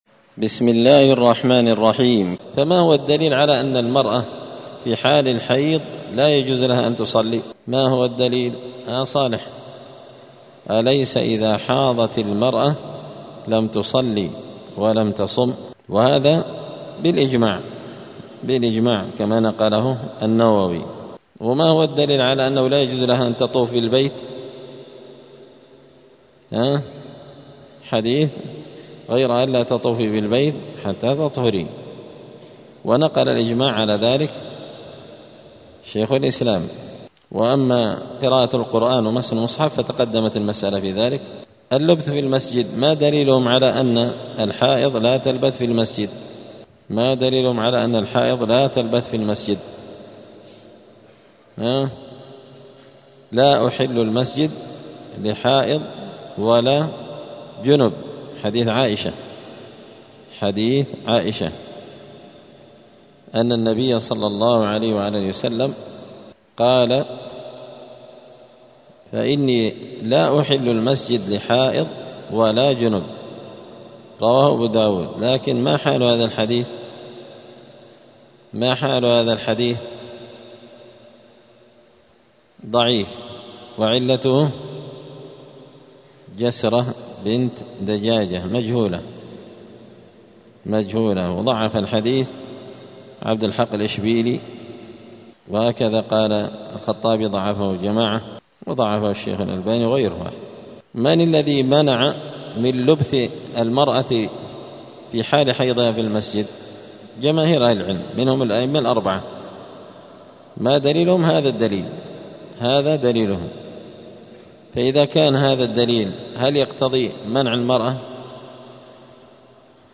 دار الحديث السلفية بمسجد الفرقان قشن المهرة اليمن
*الدرس الحادي والستون [61] {باب ما ينقض الوضوء حكم دخول المسجد للمرأة الحائض}*